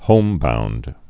(hōmbound)